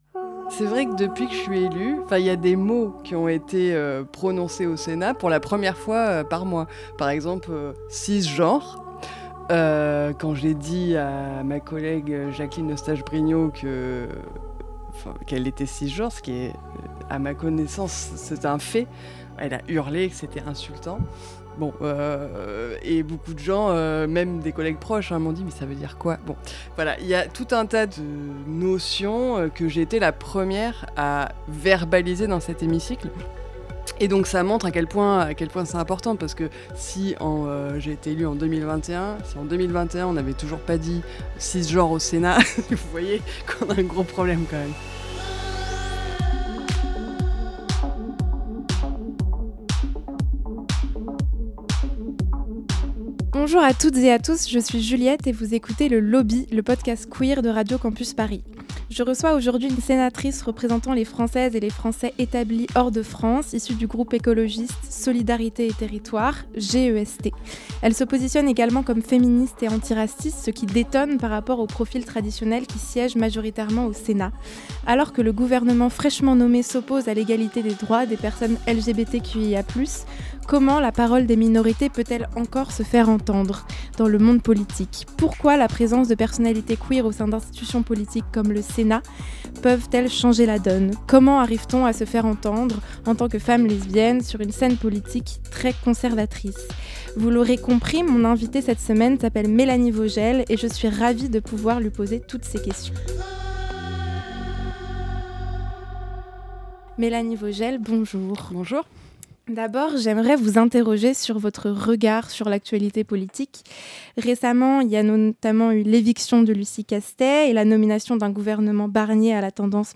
Cette semaine, on a la chance de recevoir la sénatrice écologiste lesbienne Mélanie Vogel, pour avoir son regard sur la situation politique, deux mois après la victoire de la gauche aux Législatives anticipées.